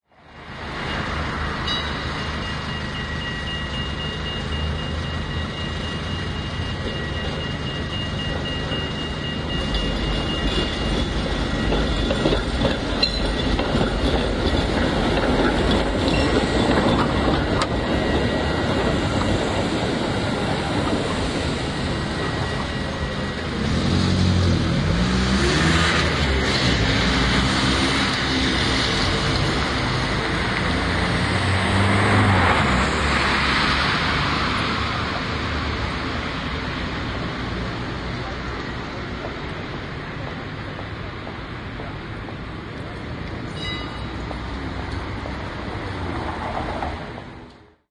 DH12 141 电车1
描述：十字路口的警告钟声，有轨电车的铃声，有轨电车加速驶离，附近道路上的交通。登哈格安静地区的城市夜景。录音。奥林巴斯LS3 + OKM双耳话筒
标签： 通车 -荷兰 -海牙 电车
声道立体声